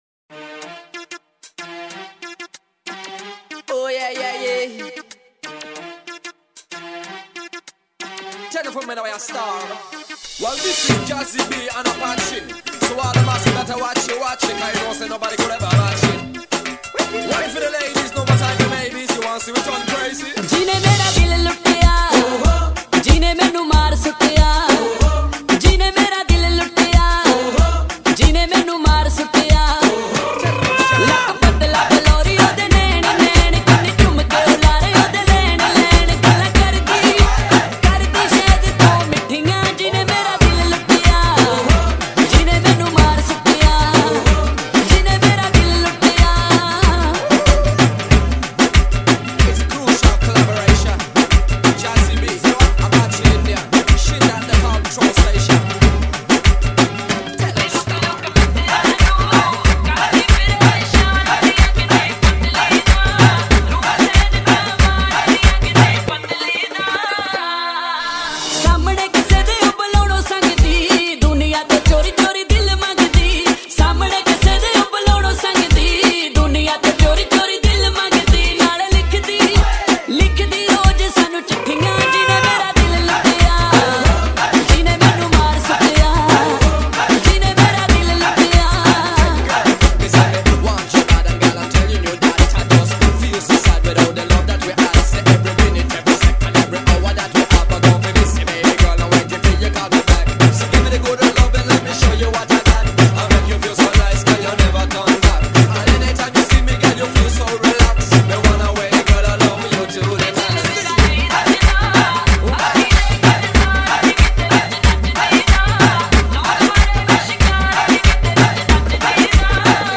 آهنگ هندی عشقی